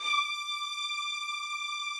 strings_074.wav